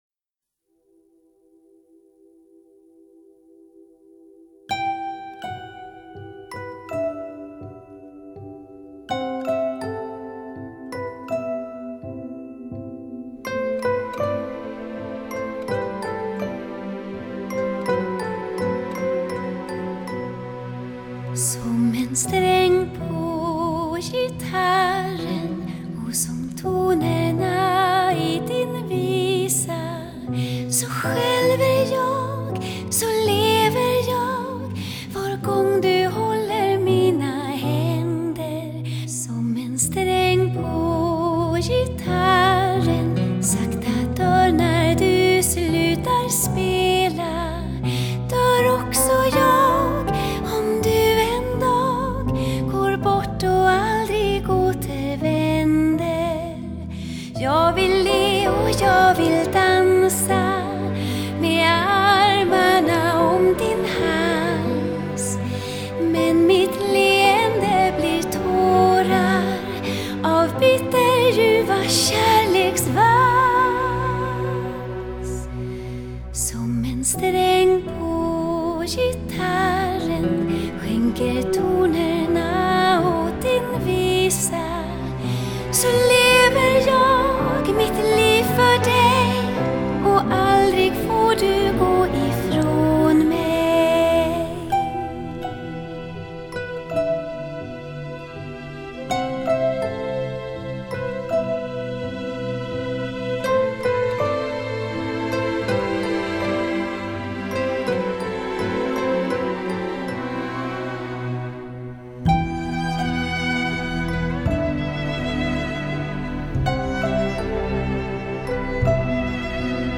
黃鶯出谷般的清亮嗓音，如微風佛面般動聽的唱腔，引領聽者進入其中，久久不能自醒。
完美無瑕的默契搭配，流露出精緻化的典雅風味，令人心曠神怡。
這張專輯無論是在音色、音質、層次、空間，乃至於音場定位都突破了舊有CD的音質，發燒效果更是向前邁進了一大步。